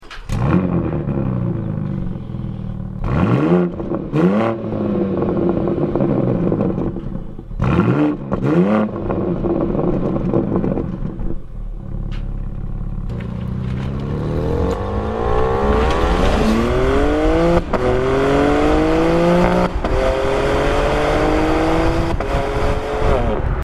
audio_bmw_m3_competition.mp3